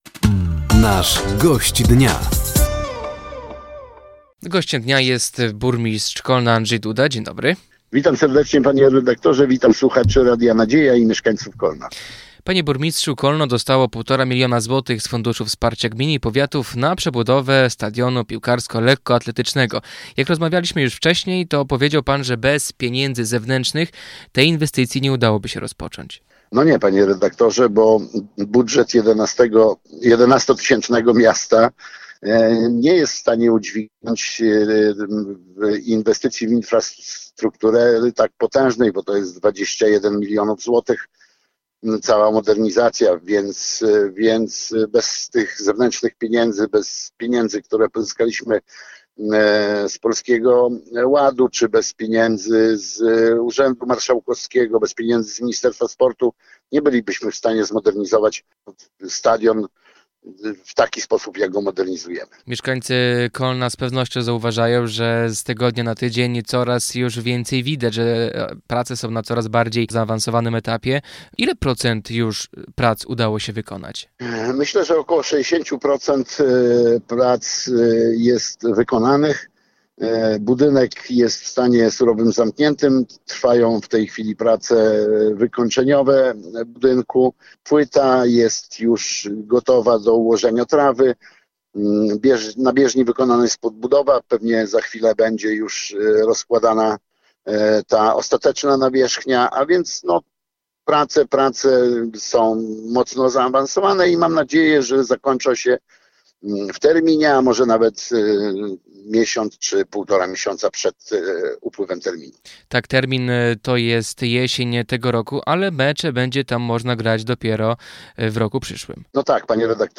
Gościem Dnia Radia Nadzieja był burmistrz Kolna, Andrzej Duda. Samorządowiec mówił między innymi o budowie stadionu, dotacjach dla Orła Kolno, podwyżkach dla pracowników i miejskich obchodach uchwalenia Konstytucji 3 Maja.